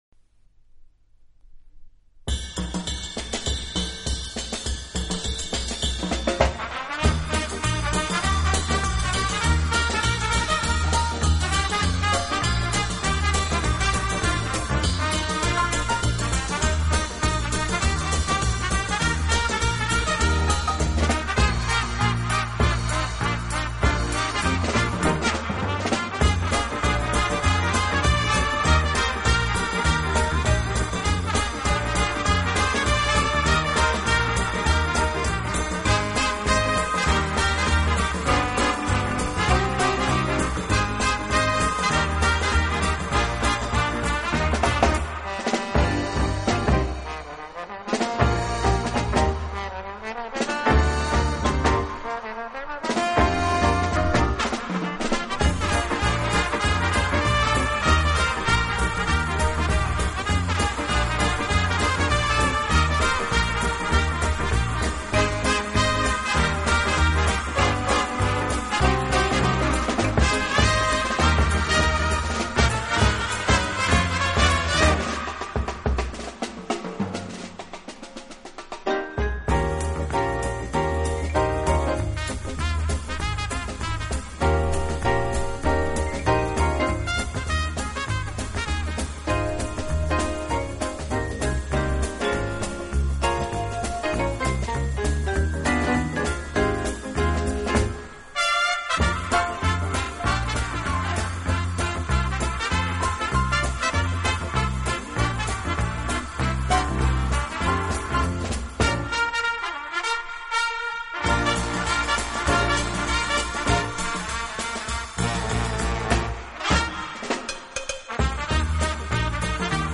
以热烈的旋律，独特的和声赢得千百万听众